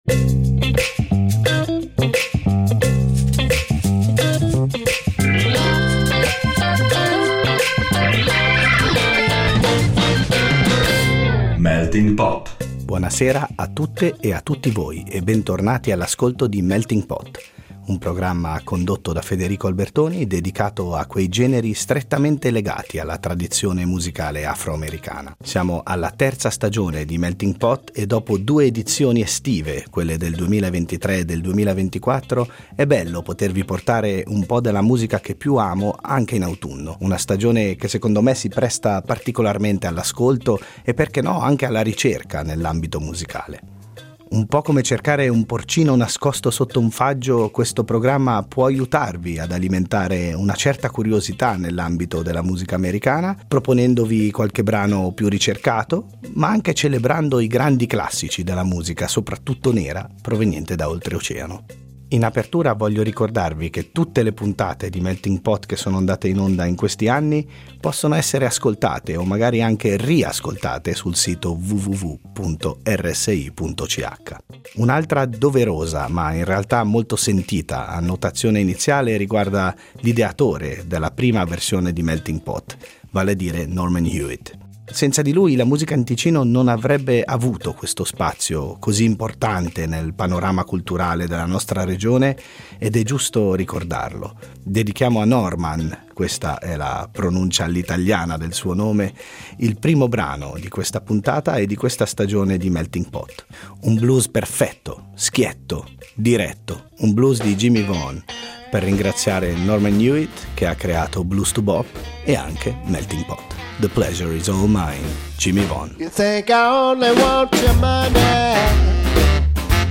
Dai maestri del blues texano alla soul music di Memphis, dalle contaminazioni del gospel al rock britannico che ha saputo reinterpretare la tradizione afro-americana, ogni scelta rivela passioni profonde e durature, componendo un mosaico personale che riflette la ricchezza della musica americana.